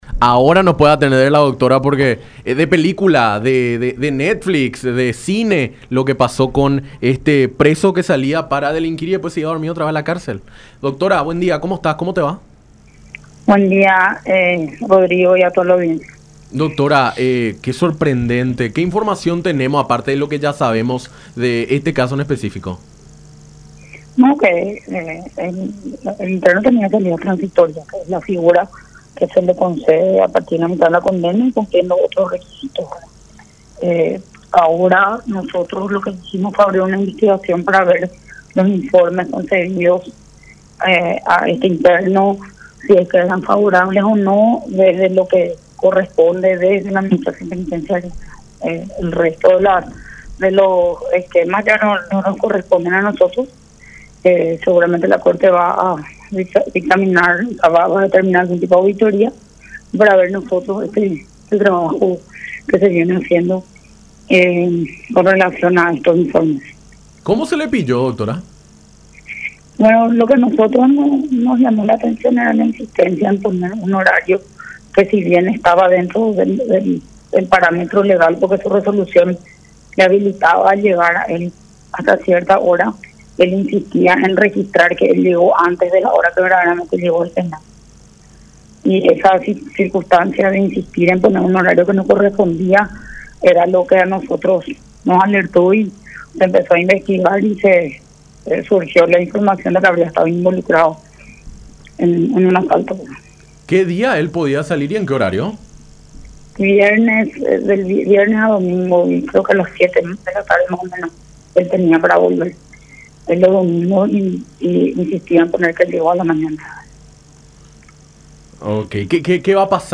Investigamos y allí surgió la información de que en ese horario él habría estado involucrado en un asalto”, explicó la ministra de Justicia, Cecilia Pérez, en diálogo con Enfoque 800 por La Unión.